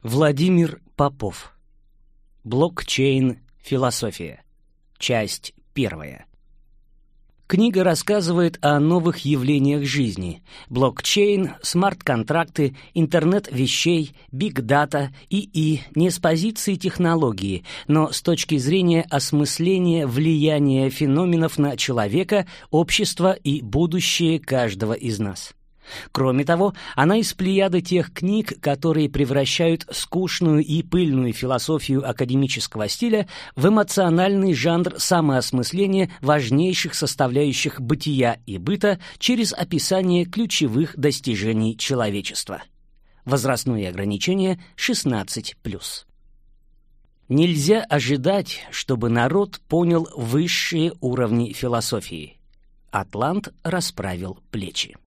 Аудиокнига Блокчейн философия. Часть I | Библиотека аудиокниг